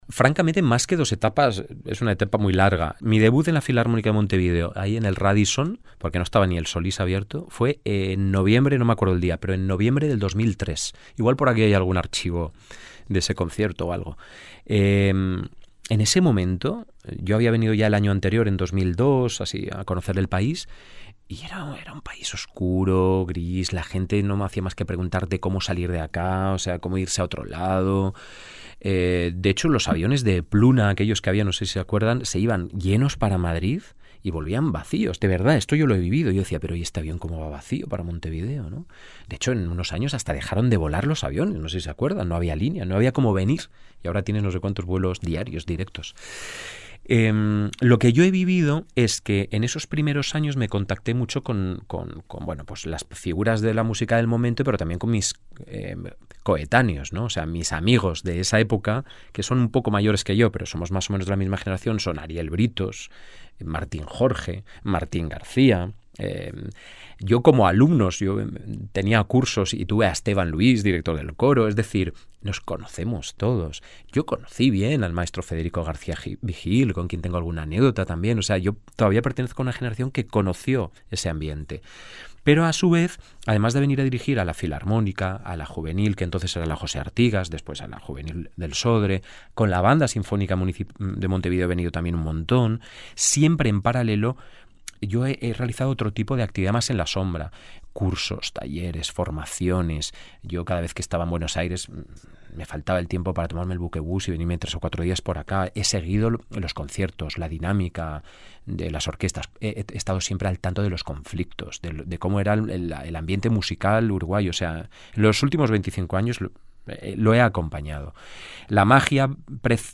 Compartimos la charla que mantuvimos a propósito del programa y su vínculo con Uruguay: